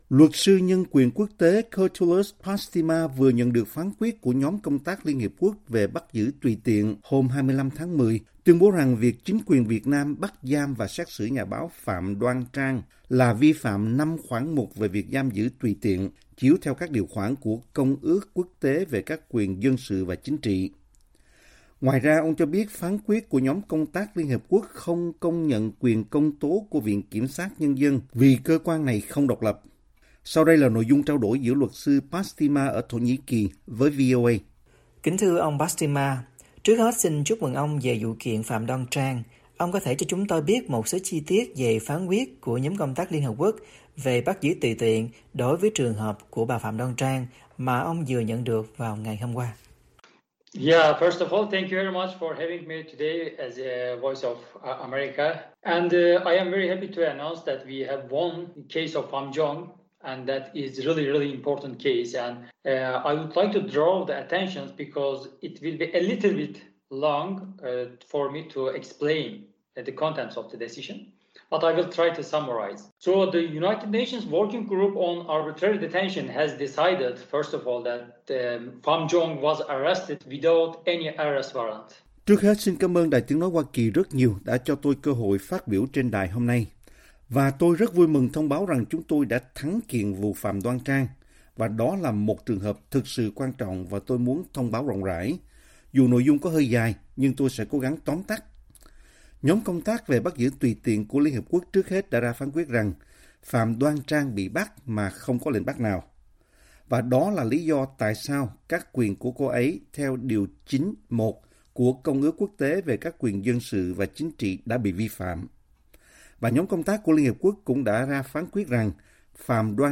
VOA phỏng vấn